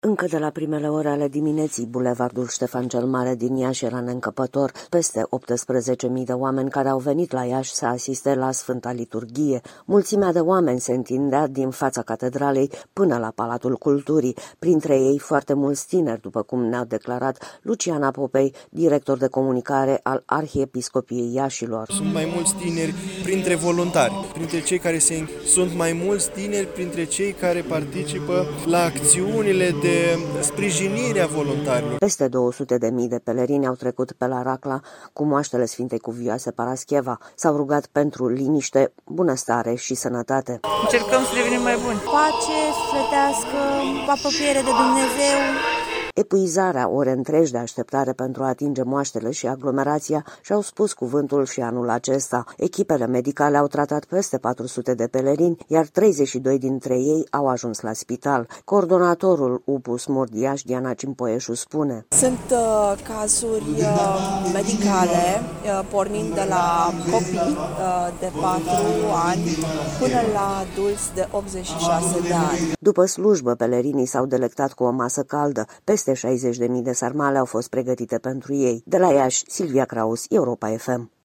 Încercăm să devenim mai buni”, a spus o credinicoasă.
„Pace sufletească, apropiere de Dumnezeu”, a spus o alta când a fost întrebată pentru ce s-a rugat.